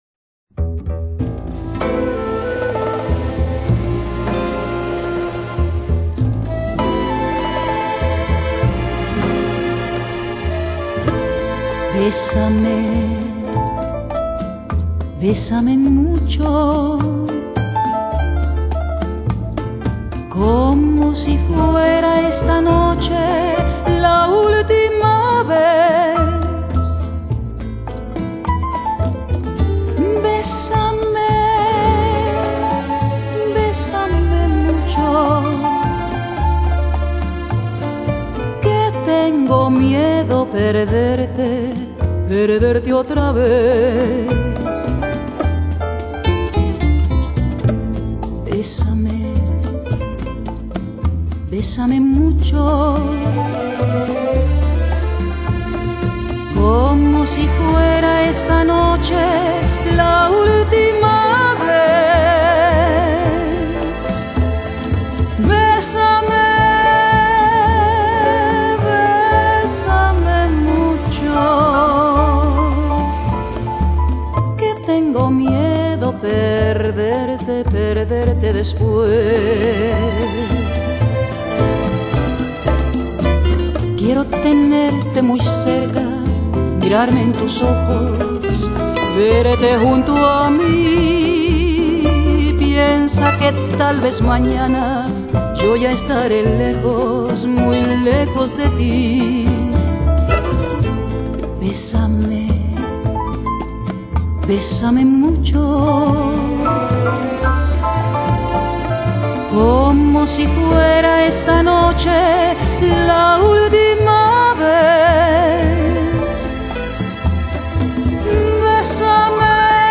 Genre: Dance / Soul / Easy Listening ...